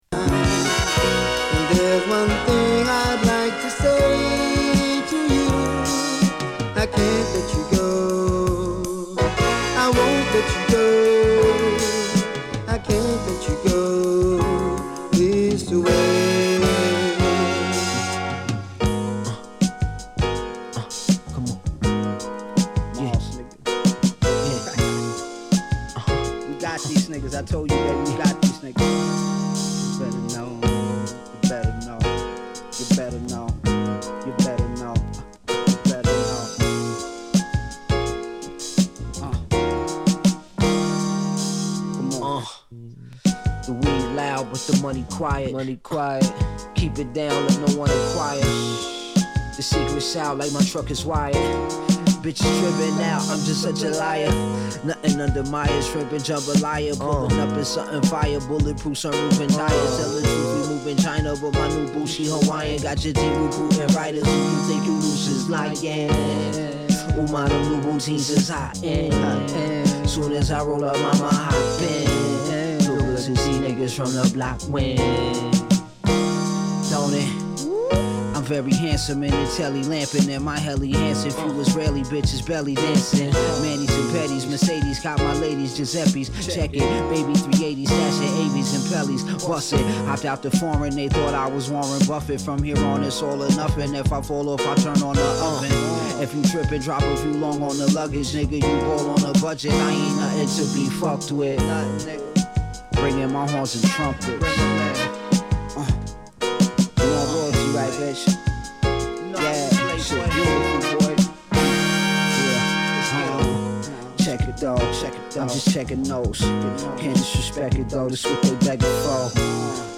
丹念に作りこまれたソウルフルなビート、ハードボイルドなライム、今作も期待を裏切らない超ドープ作。
（LPからのサンプルになります。）